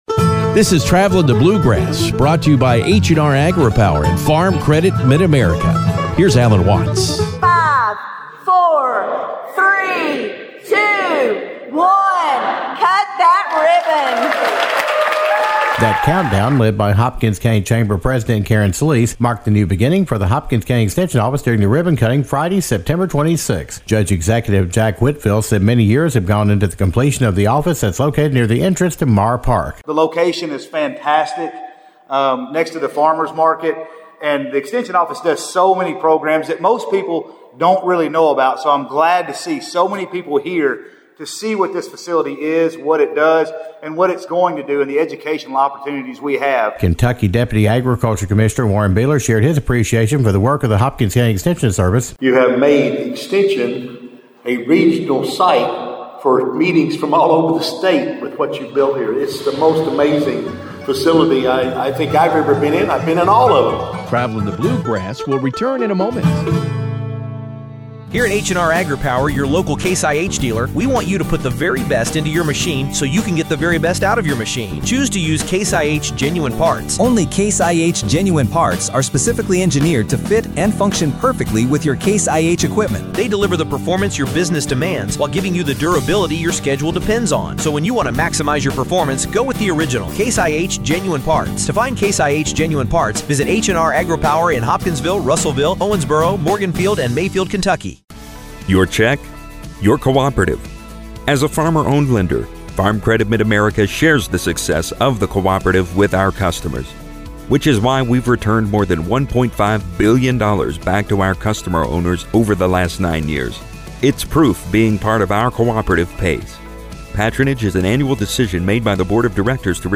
marked new beginnings for the Hopkins County Extension Office during their ribbon cutting Friday, September 26. Judge-Executive Jack Whitfield said many years have gone into the completion of the office that is located near the entrance to Mahr Park.